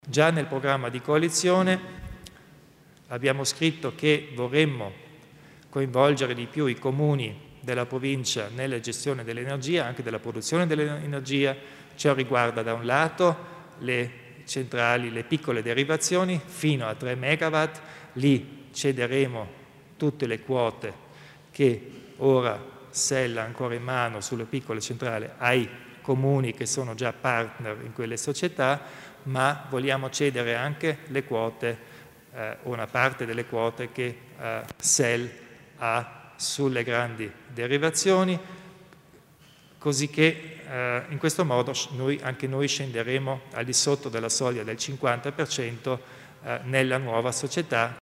Il Presidente Kompatscher illustra le novità in tema di energia
Nella conferenza stampa dopo la seduta della Giunta il presidente Arno Kompatscher ha confermato che verrà rispettato l'impegno assunto nell'accordo di coalizione di convolgere maggiormente i Comuni nella produzione e nella distribuzione di energia elettrica: una volta concluso l'auspicato percorso verso il nuovo player locale del settore, si